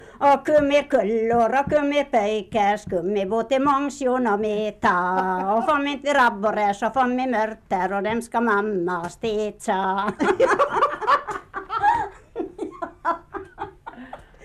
Vispolska, fiolmelodi och hornlåt